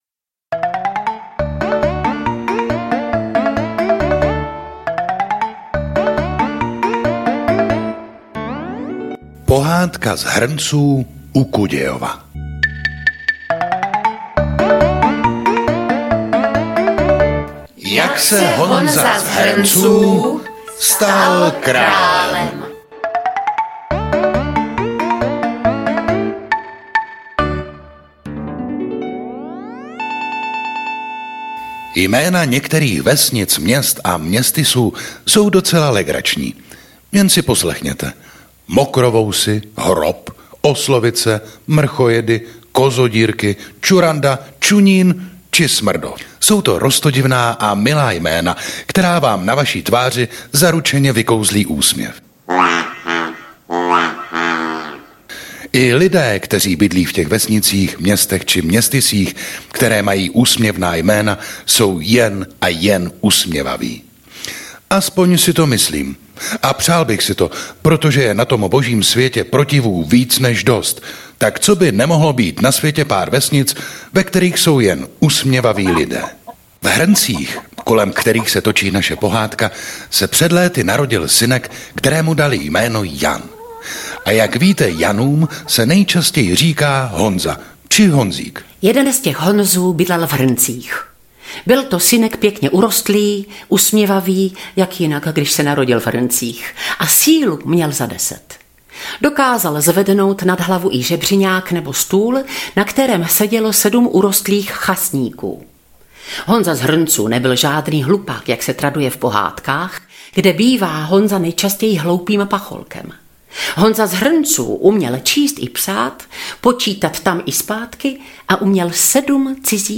Střelené pohádky audiokniha
Ukázka z knihy